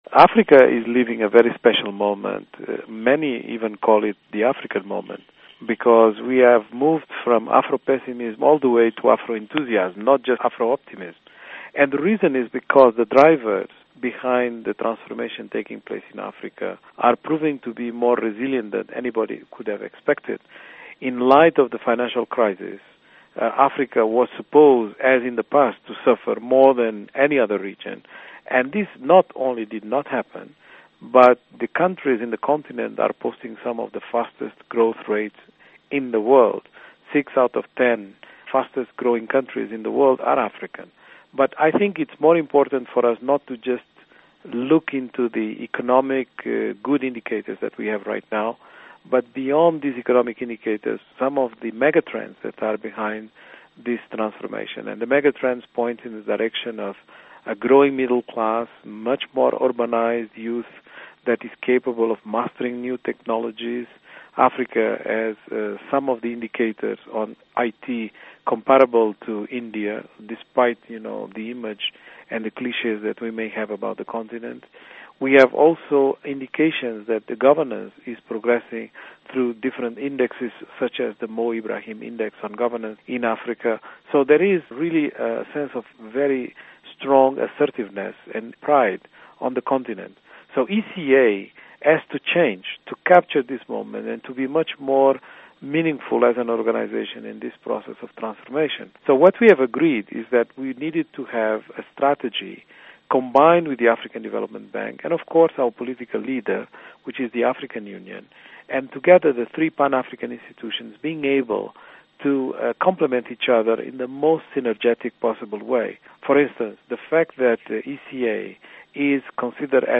Interview With Carlos Lopes